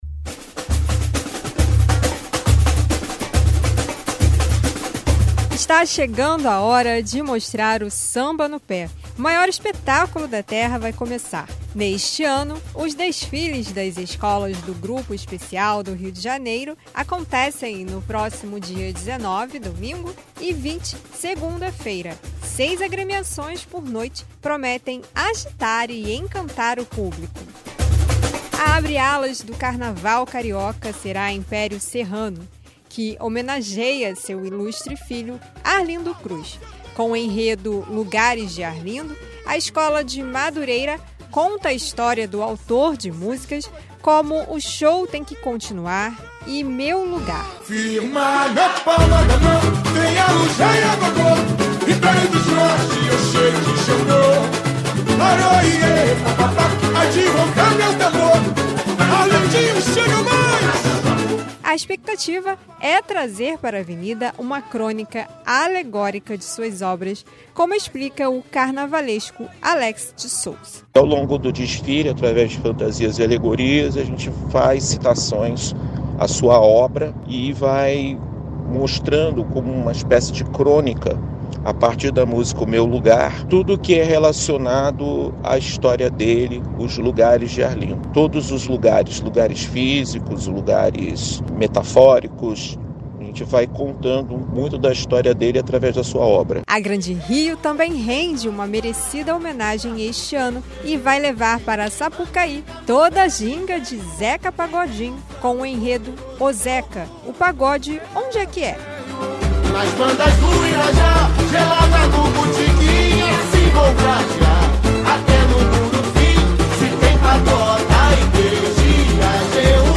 Quatro reportagens sonorizadas falam dos enredos que as escolas de samba do grupo especial do Rio trazem este ano para a avenida.